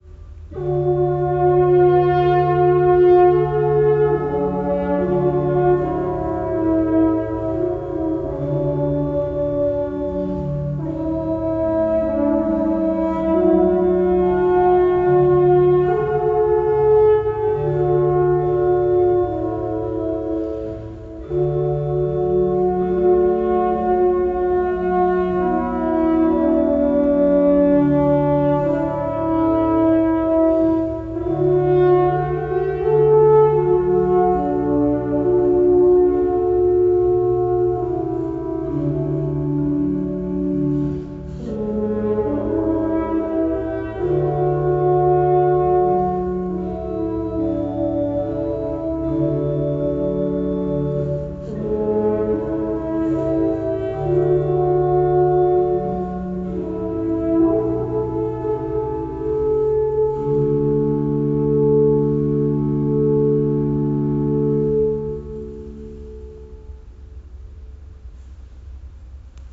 Kirchenmusikerin, Organistin
Hornistin
Kleine+Serenade+HornOrgel.mp3